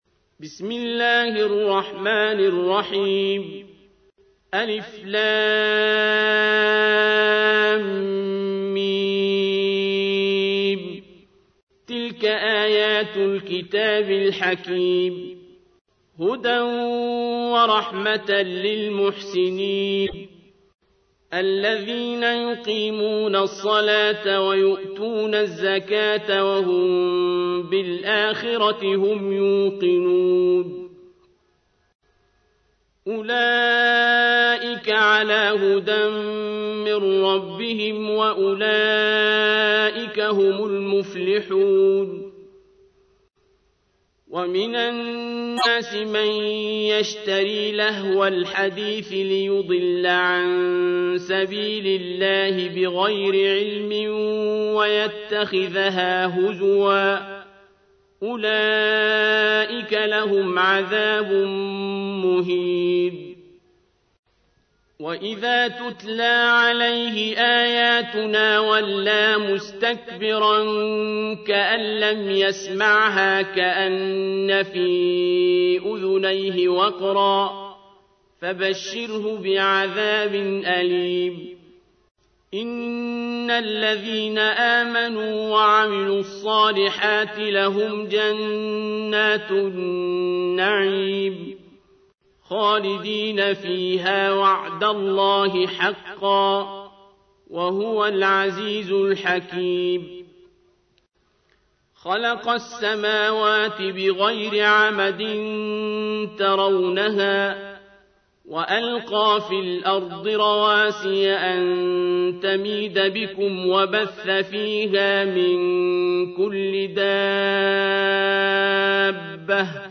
تحميل : 31. سورة لقمان / القارئ عبد الباسط عبد الصمد / القرآن الكريم / موقع يا حسين